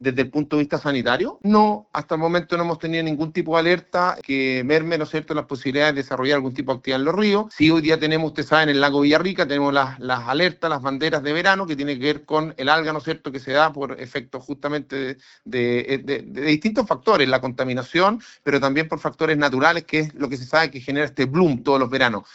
Desde Pucón, comuna vecina y de gran atractivo turístico, el alcalde Sebastián Álvarez destacó la “valentía” de Parra al poner sobre la mesa un tema que por años ha afectado a la zona lacustre.
alcalde-de-pucon.mp3